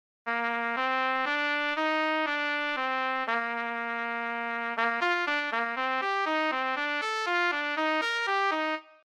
Another example. You want to have a trumpet player start on concert Bb and slur up the scale.
The first notes of each slur group are not articulated with the full tongue. The last note included in slur groups is getting tongued when it should not be.